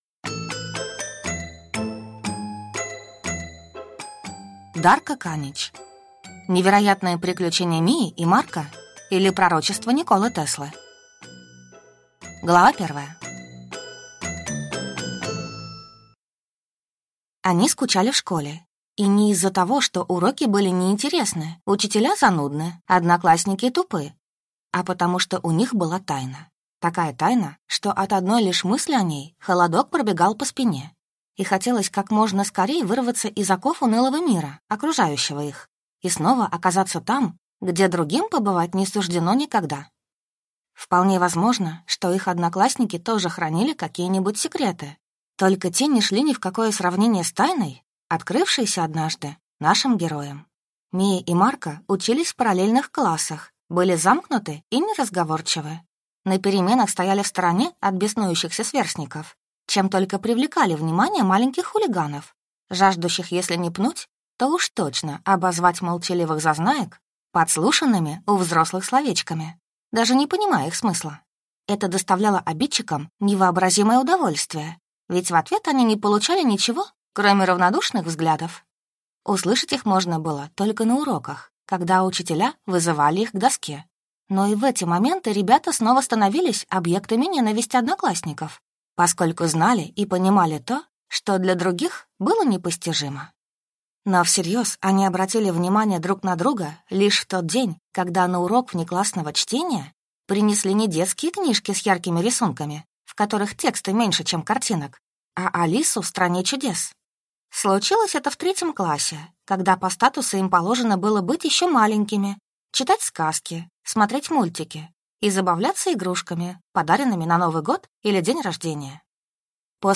Аудиокнига Невероятные приключения Мии и Марко или Пророчество Николы Теслы | Библиотека аудиокниг